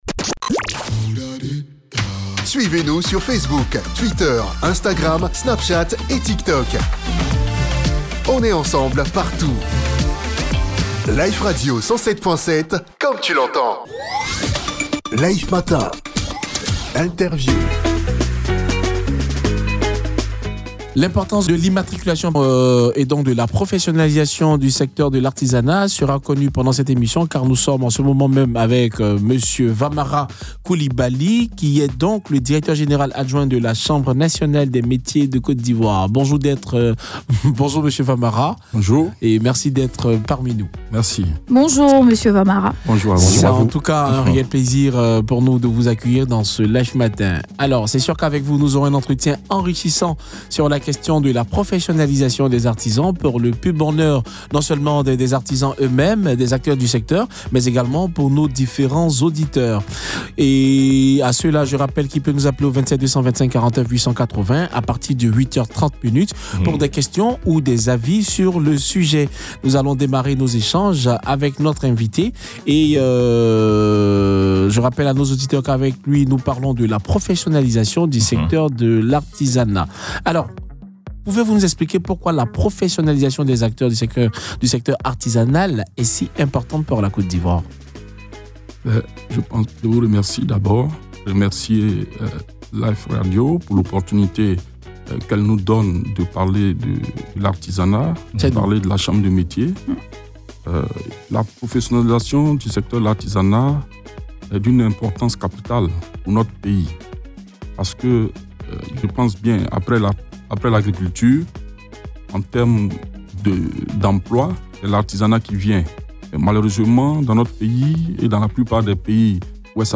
Au cours de cette interview